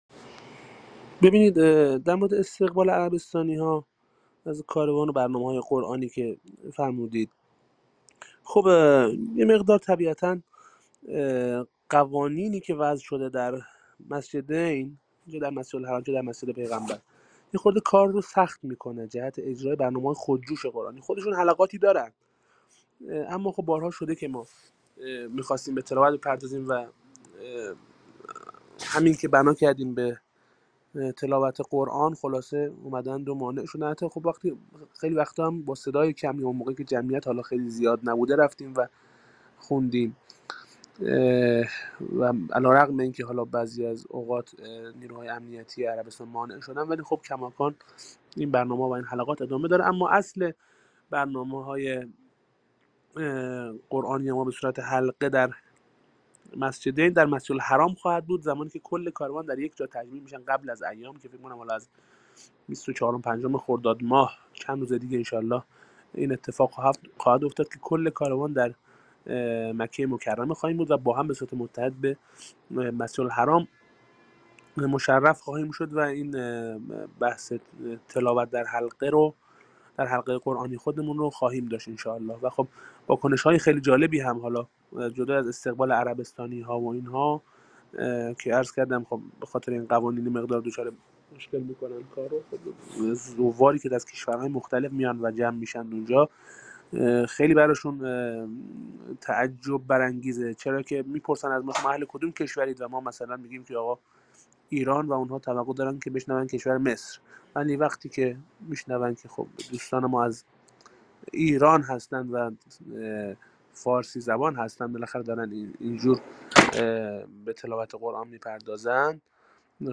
Рӯзҳои пурҷушу хуруши корвони Қуръонии Ҳаҷ; Зоирон аз қироати қориёни эронӣ истиқбол карданд + садо
Тегҳо: Корвон ، Қориён ، Тиловати Қуръон